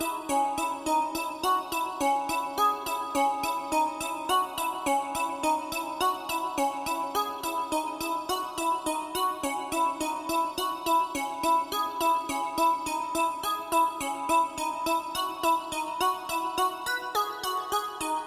Tag: 105 bpm Electronic Loops Synth Loops 3.08 MB wav Key : Unknown